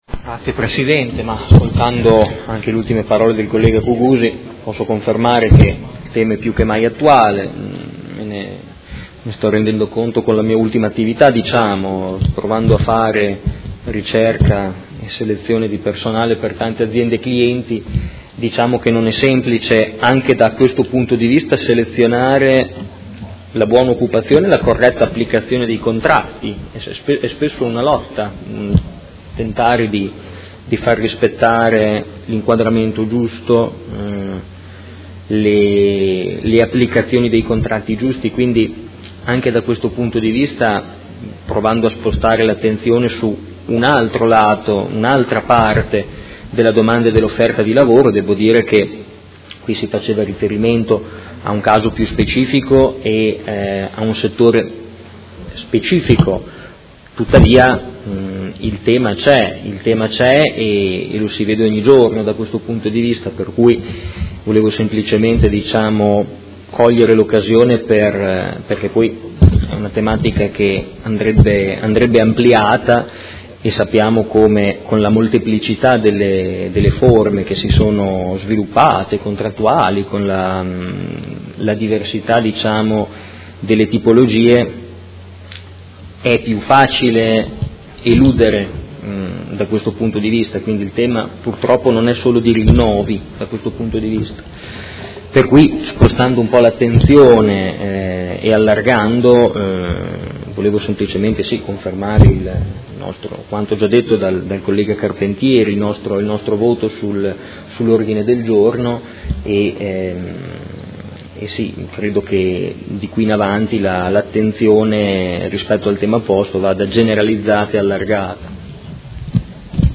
Marco Forghieri — Sito Audio Consiglio Comunale
Seduta del 28/09/2017 Mozione presentata dal Gruppo Art.1-MDP avente per oggetto: Piena solidarietà alle lavoratrici e ai lavoratori dei settori ristorazione collettiva e commerciale, pulizie e multi servizi, agenzie di viaggio. Dibattito